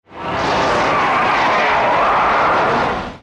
File:Kaishin muba roar.mp3
Kaishin_muba_roar.mp3